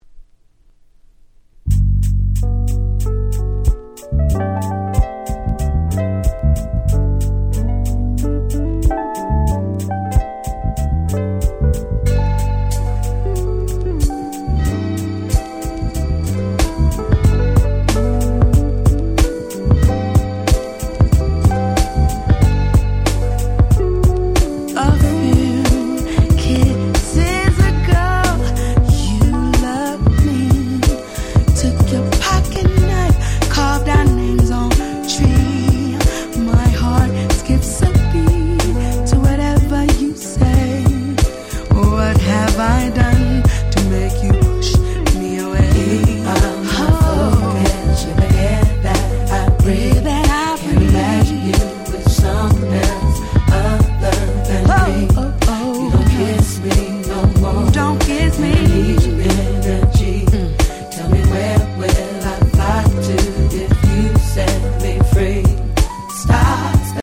01' Very Nice R&B / Neo Soul !!